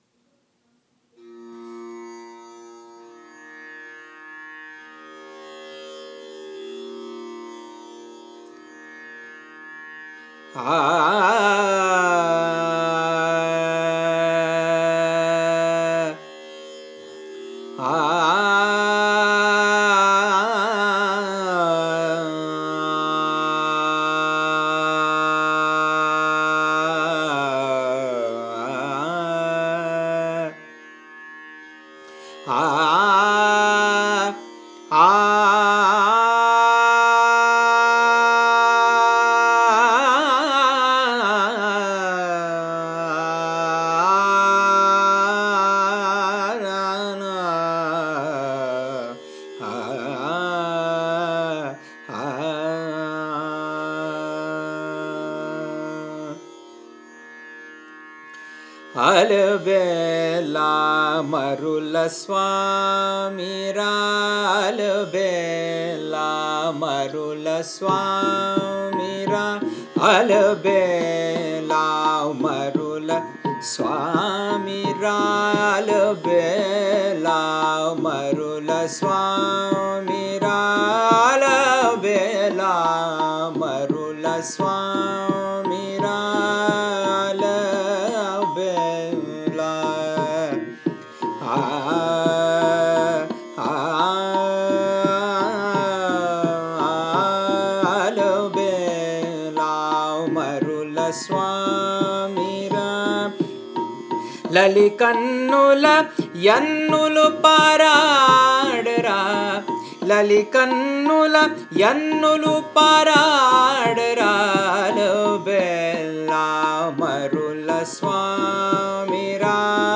రాగం: అహిర్ భైరవ్ (చక్రవాకం)